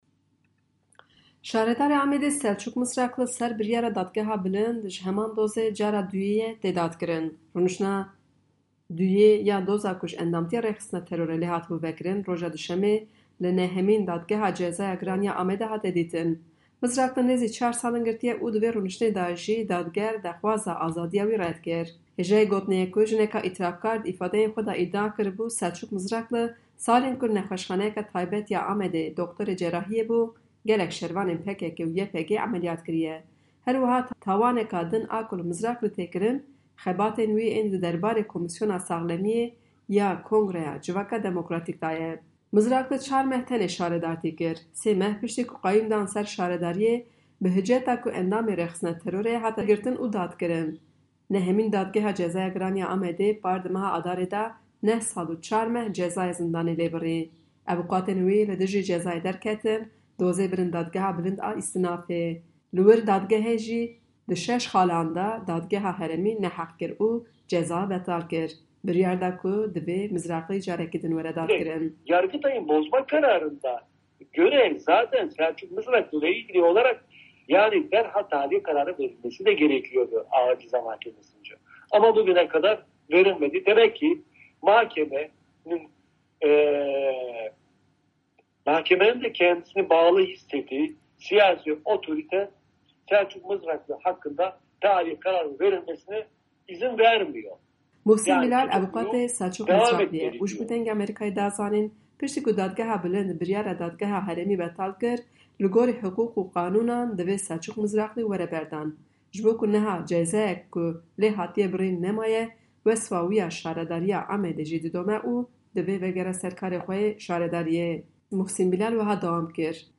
KHF_Raporta_Taybet_Selcuk_Mizrakli